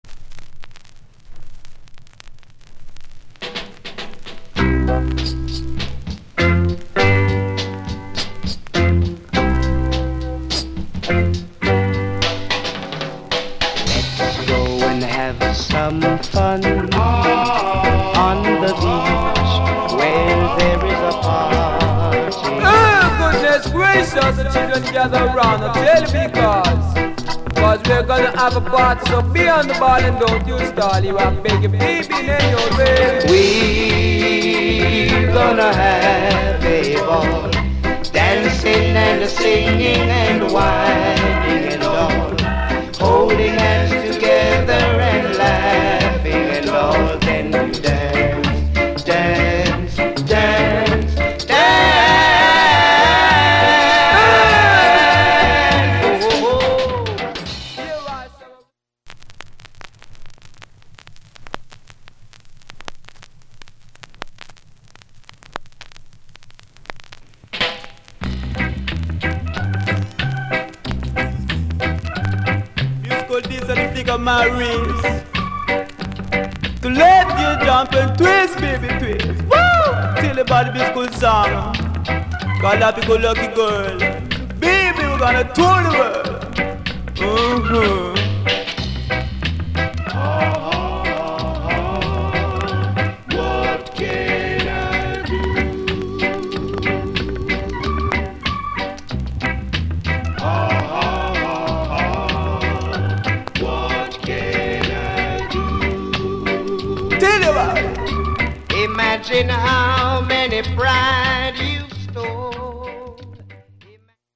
Genre Reggae70sEarly / Male Vocal Male DJ